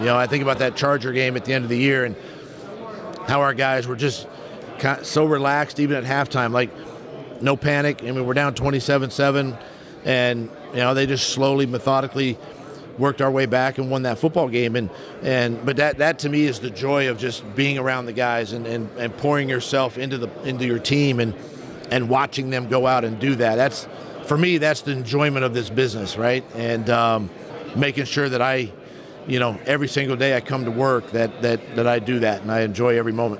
Jacksonville Jaguars head coach Doug Pederson speaks during the AFC head coaches availability at the NFL meetings, Monday, March 27, 2023, in Phoenix.
Speaking to the media about the Wild Card victory, Pederson described his feelings as pure joy.